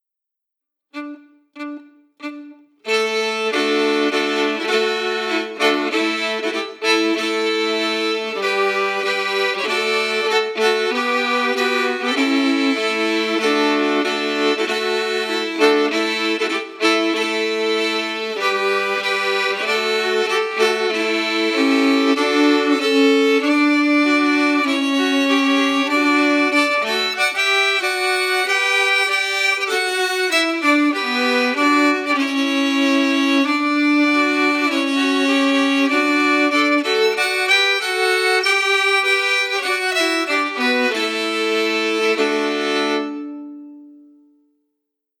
Key: D*
Form: March (marching song)
Harmony emphasis
Genre/Style: Scottish marching song